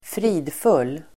Uttal: [²fr'i:dful:]